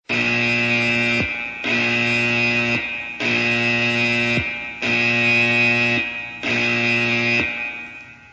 Klingelton Navy Alarm
Kategorien Alarm